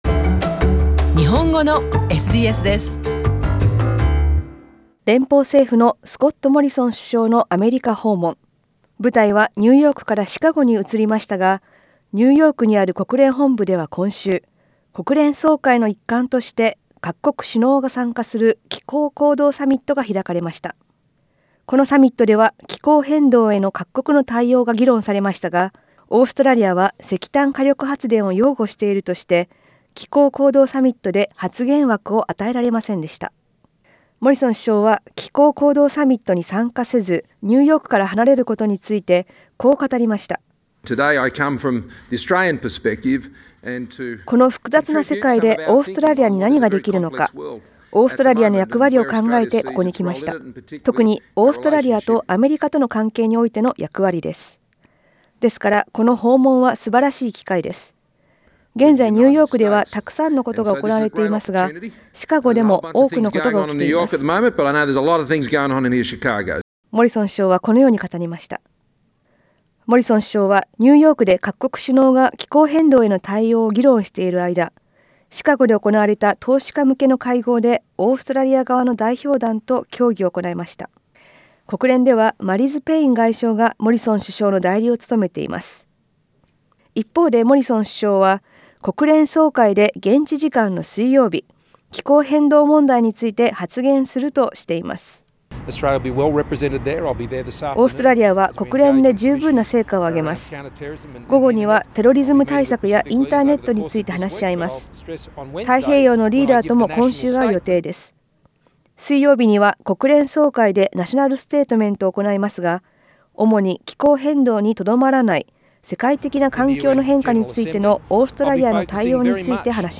詳しくは写真をクリックして音声リポートをどうぞ。